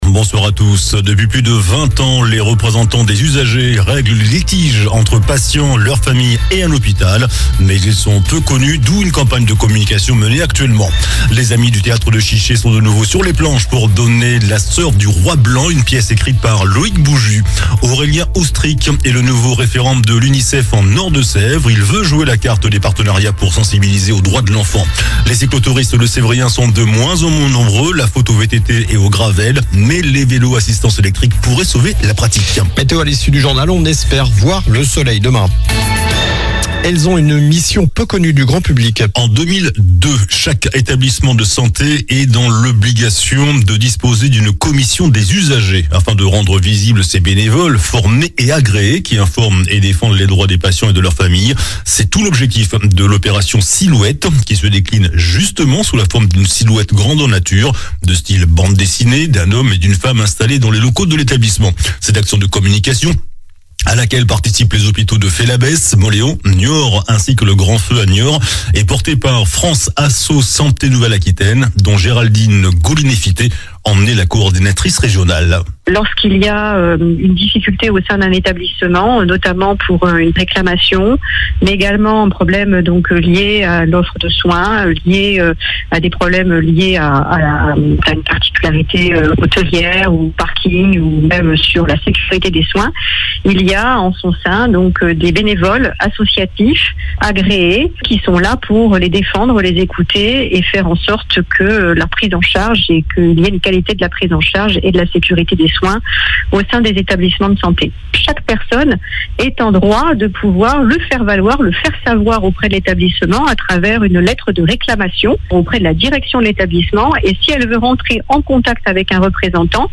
JOURNAL DU MARDI 10 DECEMBRE